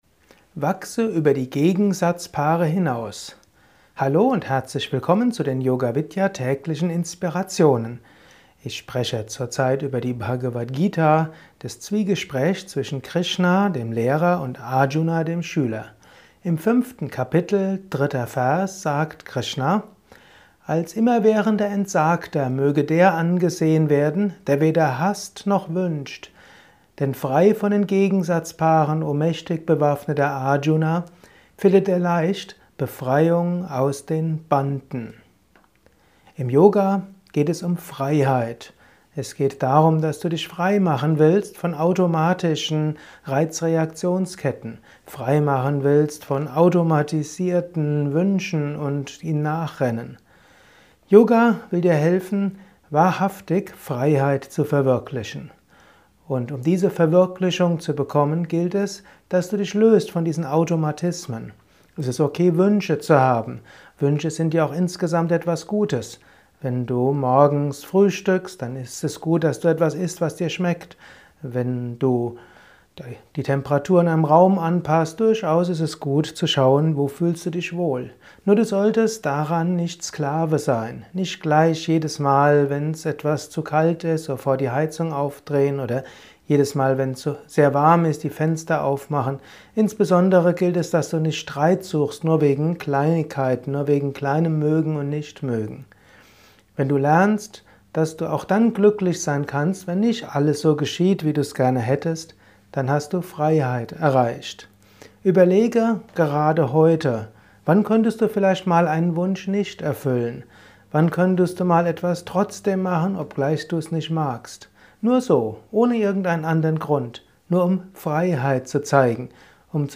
Kurzvorträge